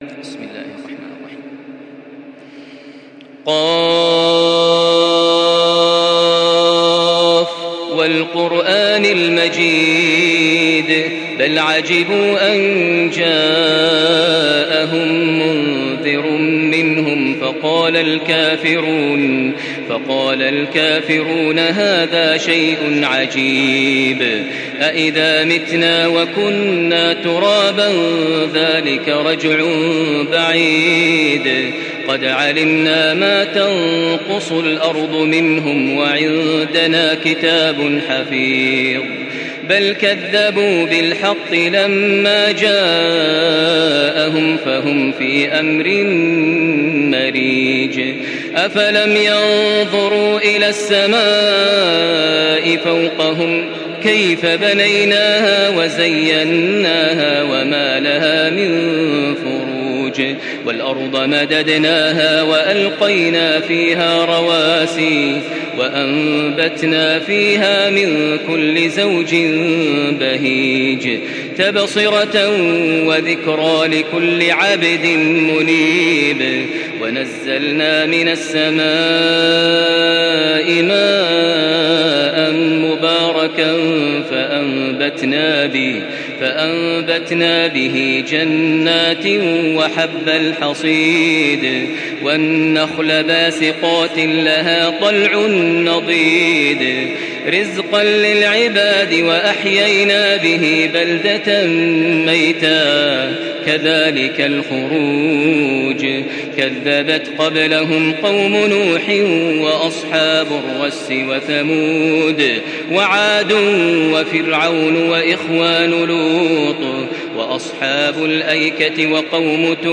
Surah Kaf MP3 by Makkah Taraweeh 1435 in Hafs An Asim narration.
Murattal